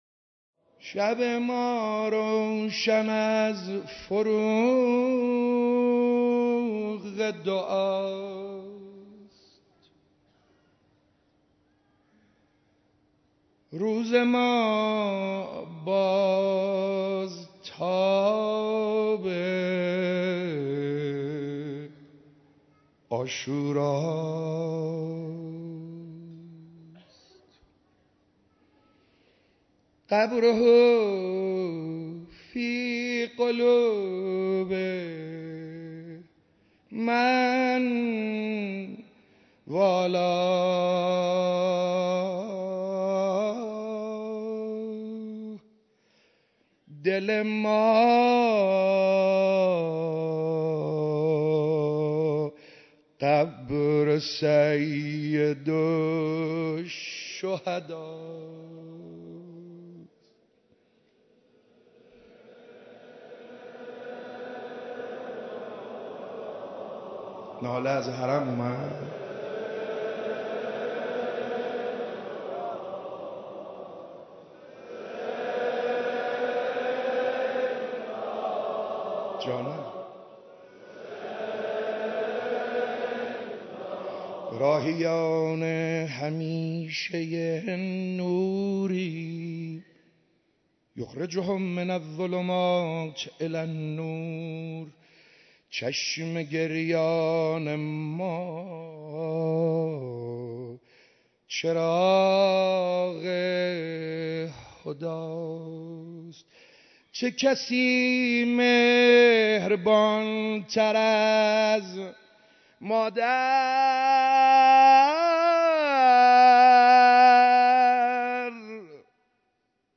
دومین شب مراسم عزاداری حضرت اباعبدالله الحسین علیه‌السلام
روضه